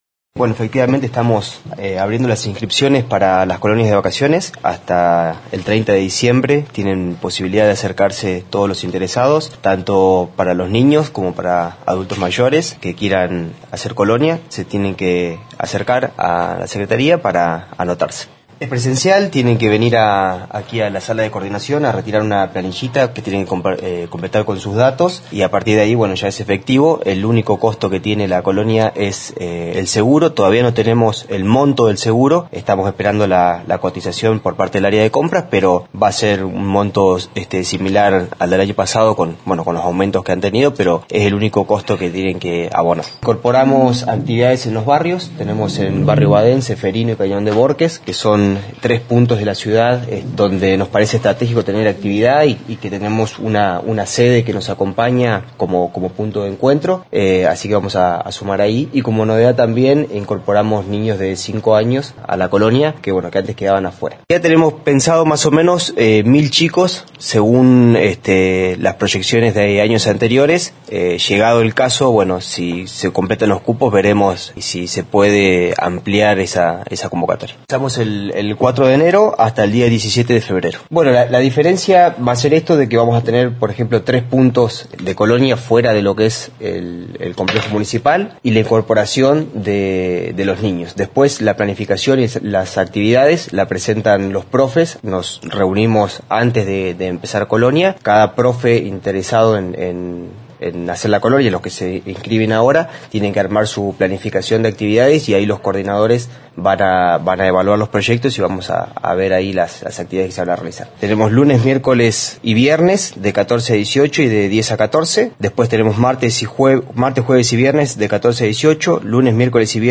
En conferencia de prensa se anunció la apertura de las inscripciones para las Colonias de Vacaciones, que se desarrollarán en los meses de enero y febrero del 2023.